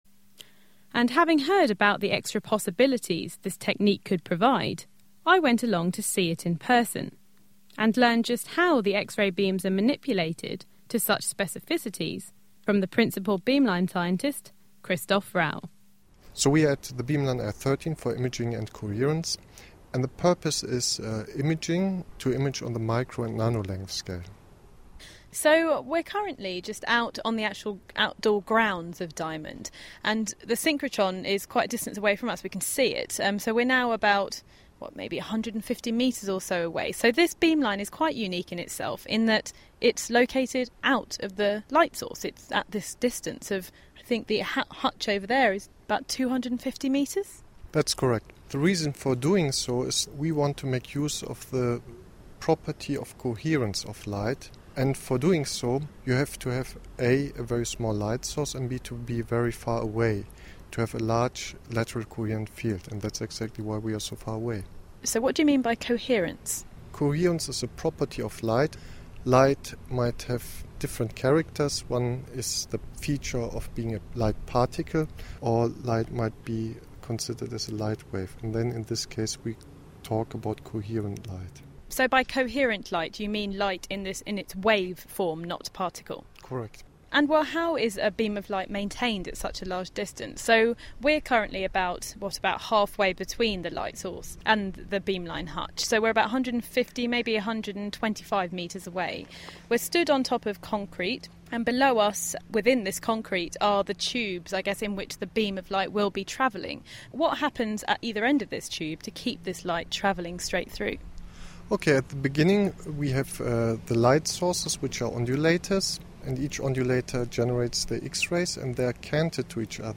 Interviews with Scientists
On Location at the Imaging and Coherence Beamline
On-location at the Imaging and Coherance Beamline...